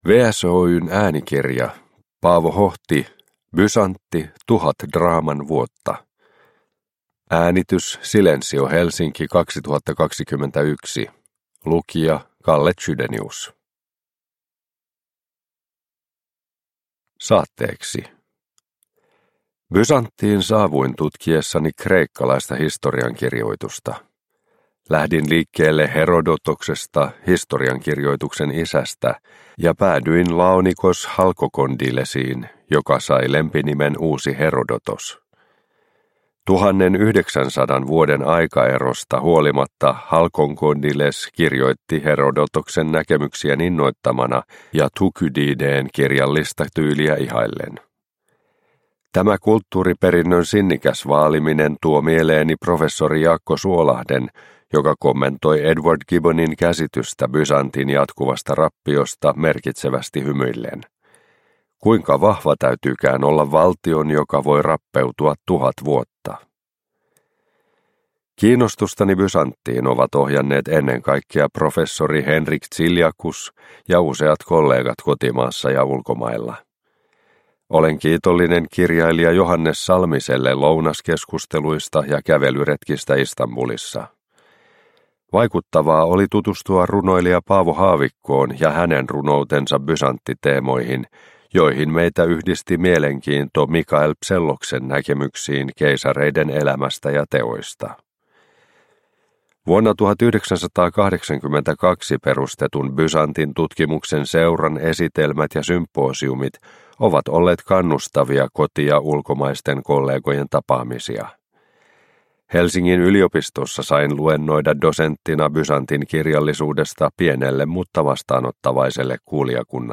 Bysantti – Tuhat draaman vuotta – Ljudbok – Laddas ner